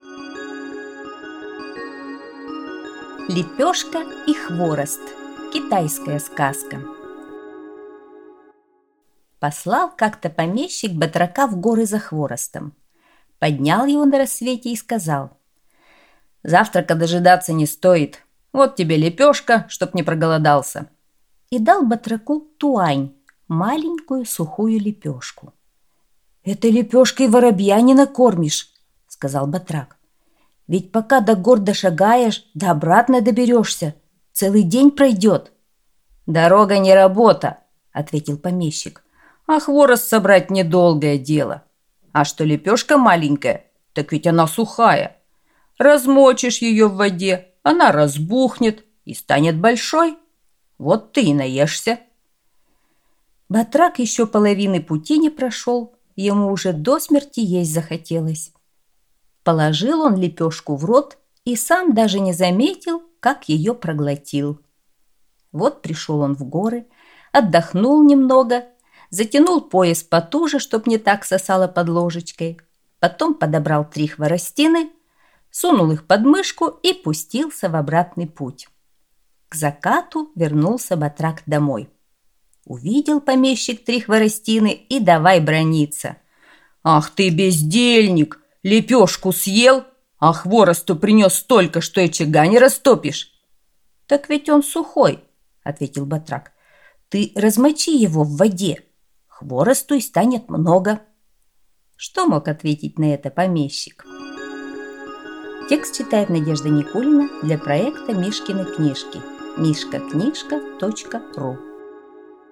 Лепёшка и хворост – китайская аудиосказка